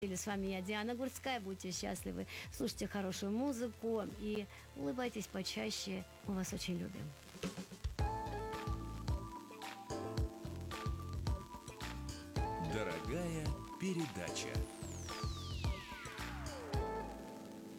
А вот после окончания передачи действительно прозвучала электронная композиция, но всего лишь несколько тактов и её заглушил диктор...
Отрывок из передачи: